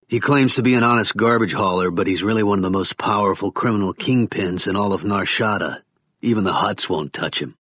Kyle Katarn to Luke Skywalker — (audio)